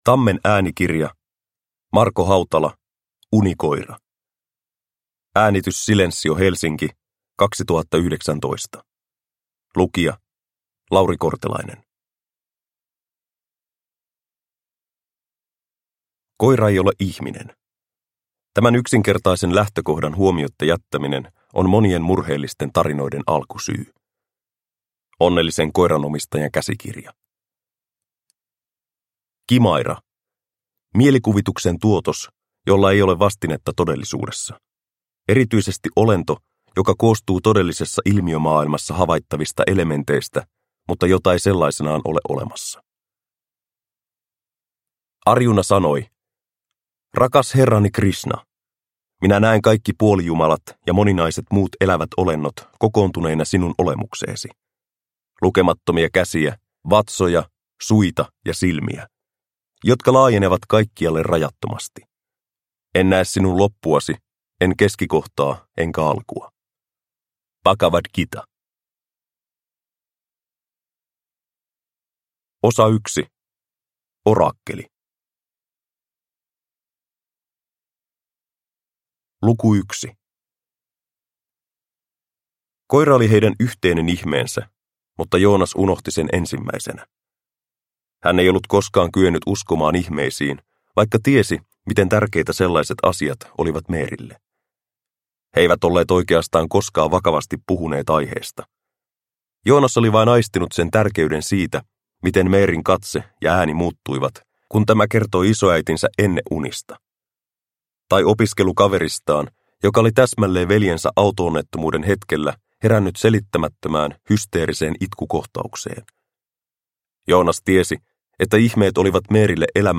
Unikoira – Ljudbok – Laddas ner